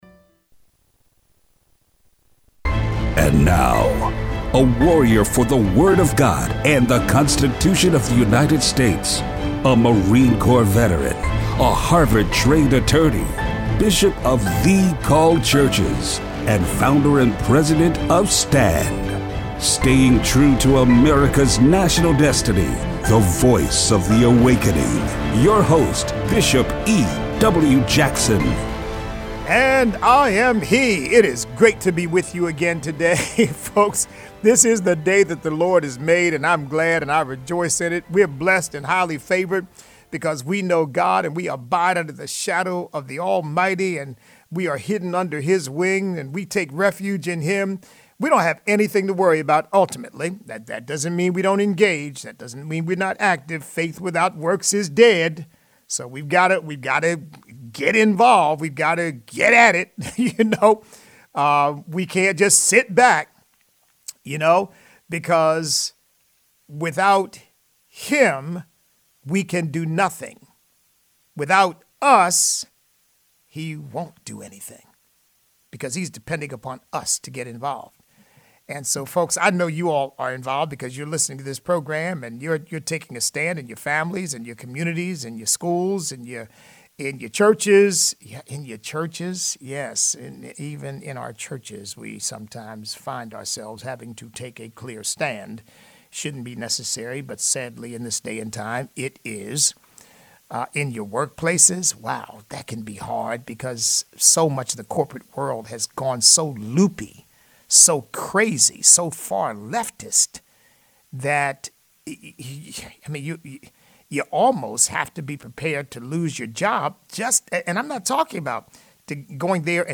Show Notes Christianity Today has become a tool of the Left. Listener call-in.